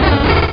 cry_not_munchlax.aif